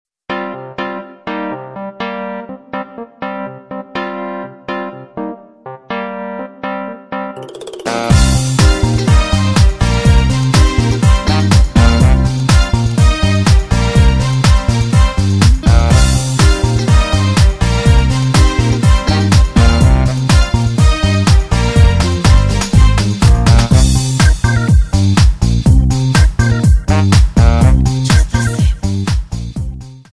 With Backing Vocals